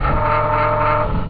ROBOTIC_Servo_Large_Slow_mono.wav